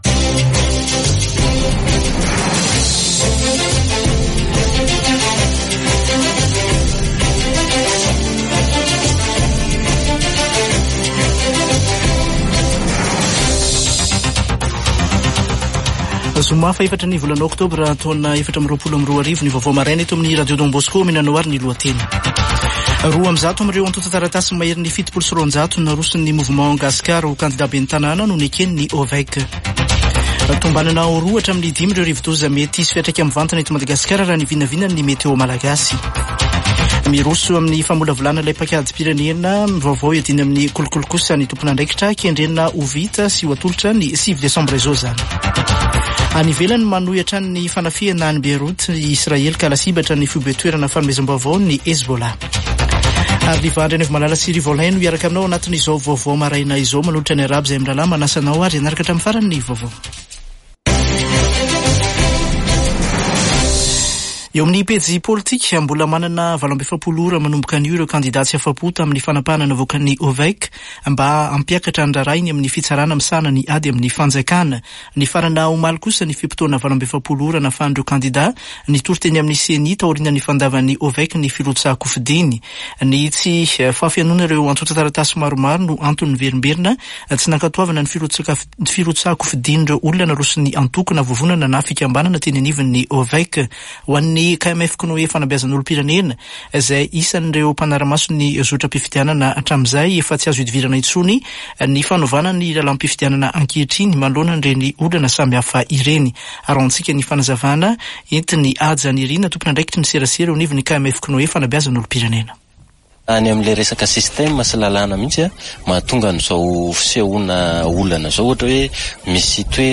Radio Don Bosco - [Vaovao maraina] Zoma 4 ôktôbra 2024